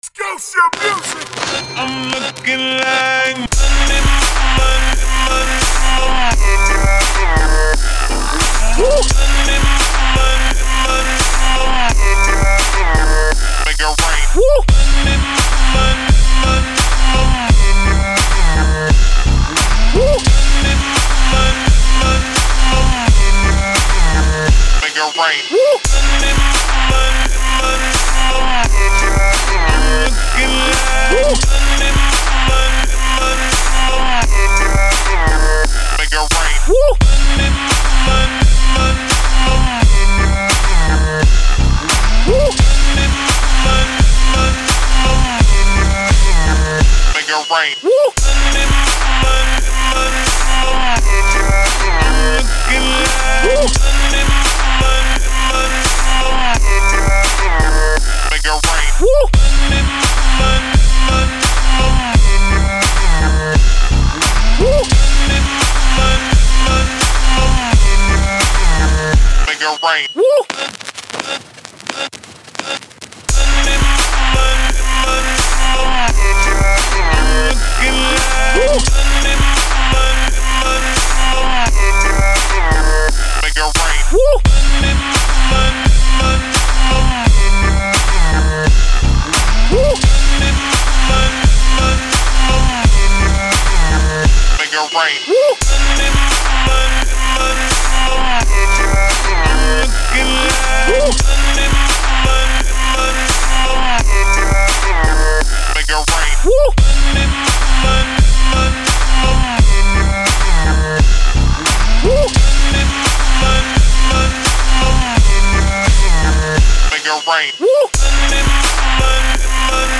Beats That Make Your Neighbors Come Knocking